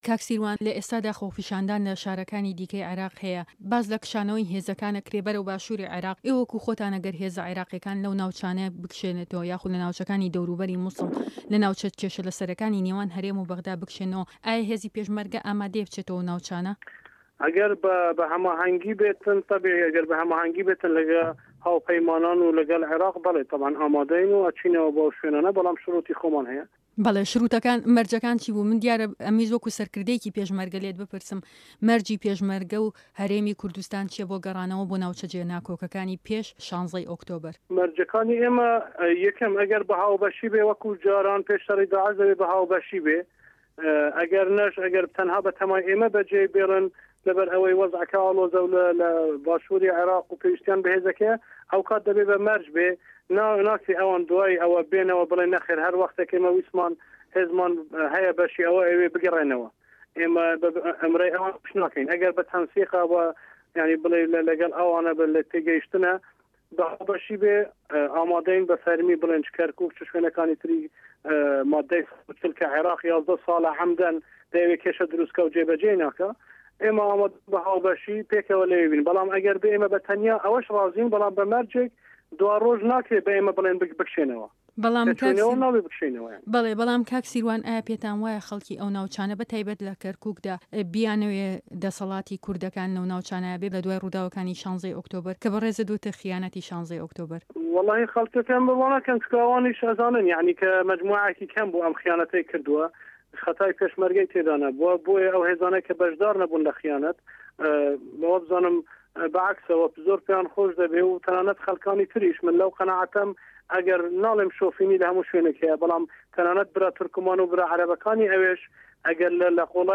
ده‌قی وتووێژه‌كه‌ی به‌شی كوردی ده‌نگی ئه‌مریكا له‌گه‌ڵ به‌ڕێز سیروان بارزانی